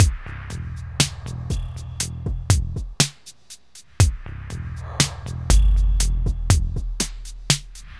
Downtempo 20.wav